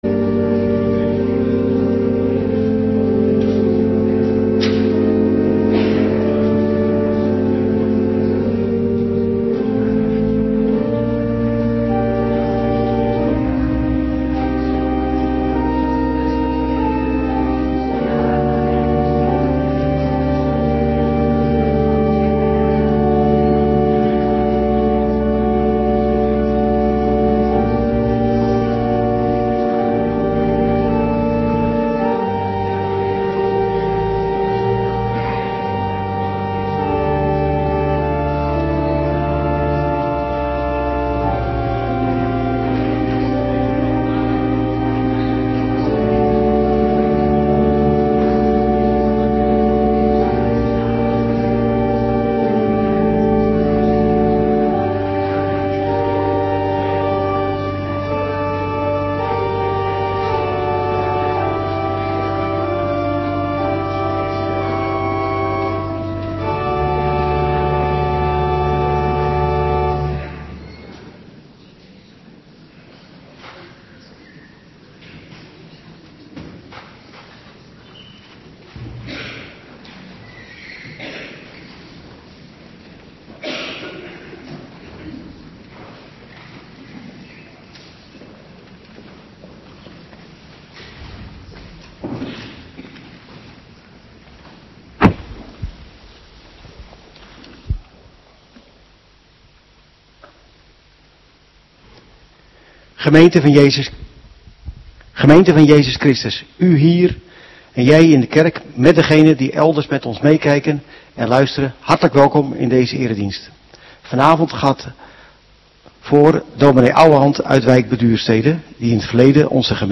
Avonddienst 15 maart 2026